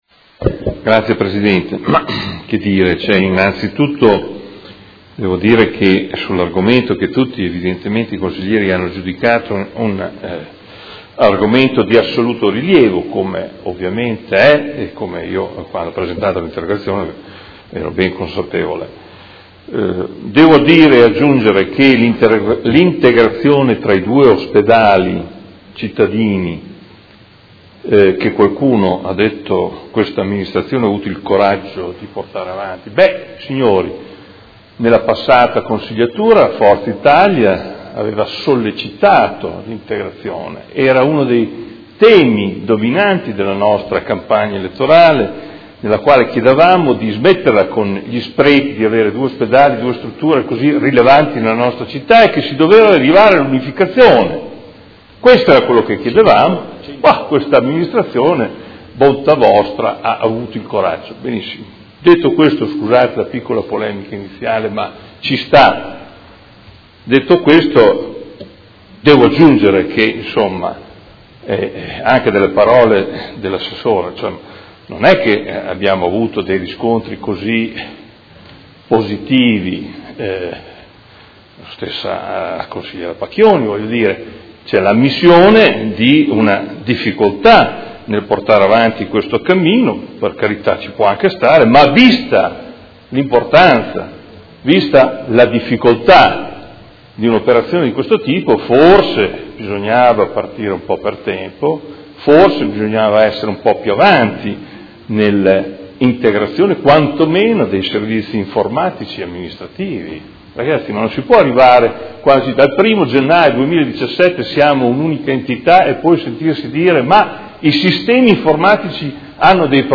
Seduta del 23/03/2017 Replica a risposta Assessore. Interrogazione del Consigliere Morandi (FI) avente per oggetto: A che punto è arrivata l’integrazione funzionale tra il Policlinico e l’Ospedale di Baggiovara?